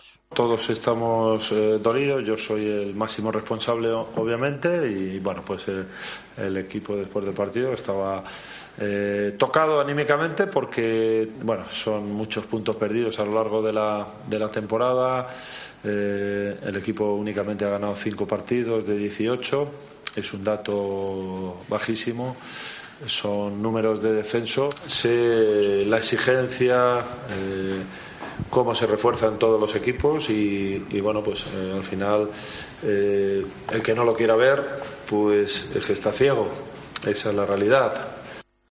AUDIO: El entrenador del Valencia habló tras la derrota ante el Betis sobre la mala temporada del equipo y el mal estado anímico de los jugadores.